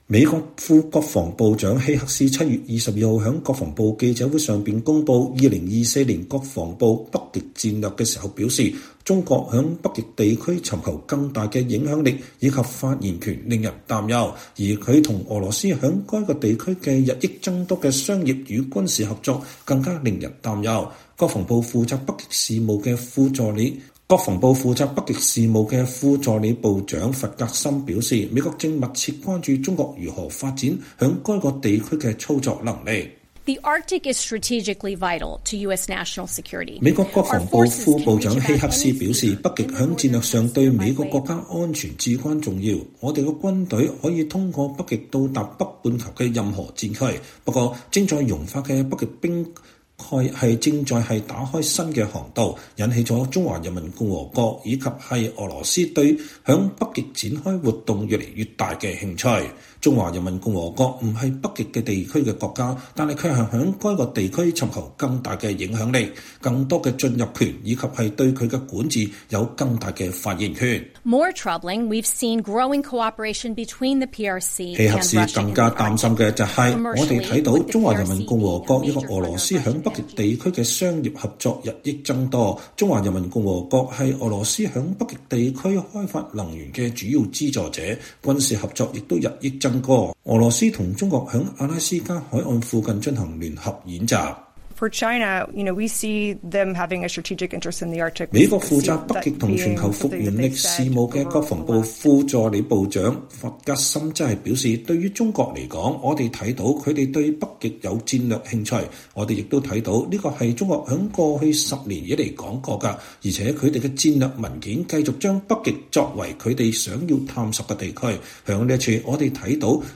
美國副國防部長凱瑟琳希克斯7月22日在國防部記者會上公佈《2024年國防部北極戰略》時說，中國在北極地區尋求更大的影響力和發言權令人擔憂，而它與俄羅斯在該地區日益增多的商業與軍事合作更令人擔憂。國防部負責北極事務的副助理部長艾里斯·弗格森說，美國正密切關注中國如何發展在該地區的操作能力。